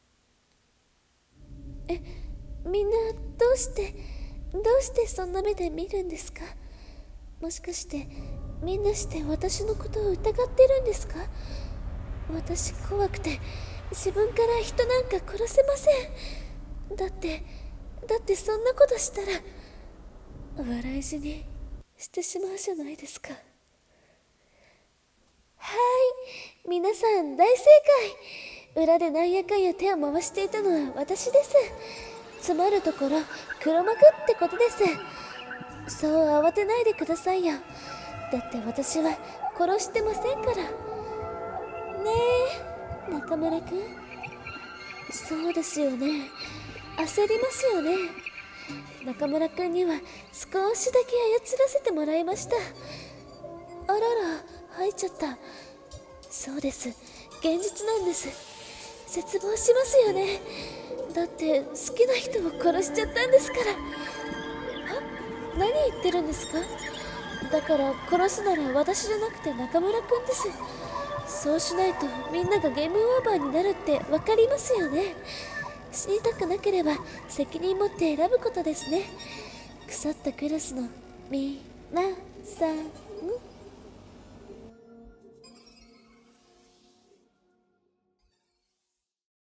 声劇 黒幕